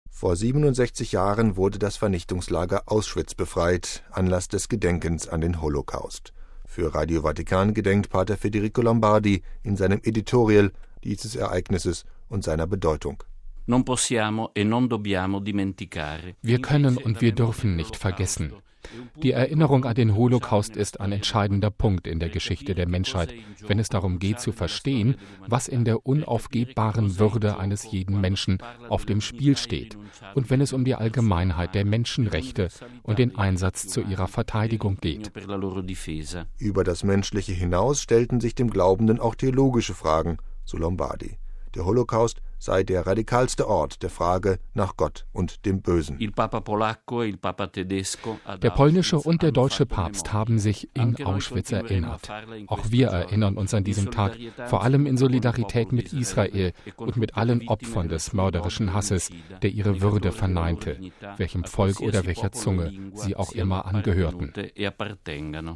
Für Radio Vatikan erinnert Pater Federico Lombardi in seinem Editorial an dieses Ereignis und seine Bedeutung: